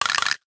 NOTIFICATION_Rattle_11_mono.wav